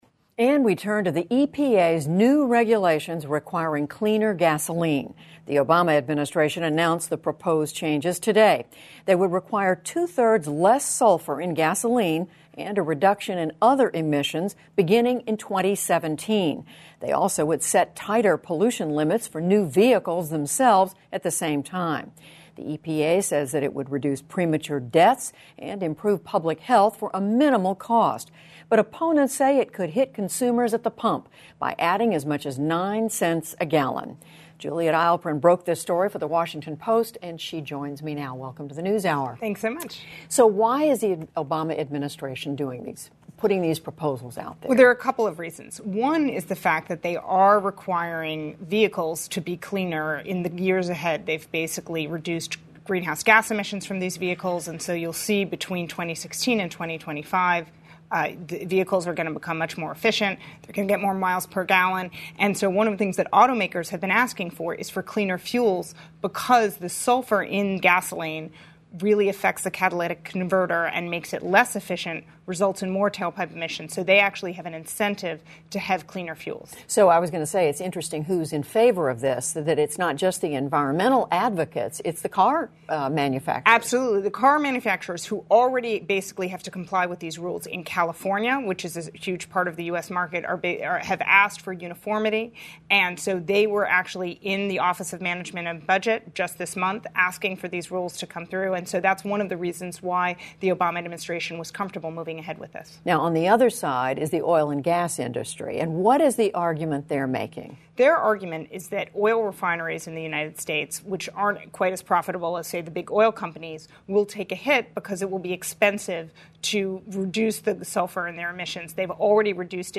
英语访谈节目:美国环保署推进净化温室气体排放的建议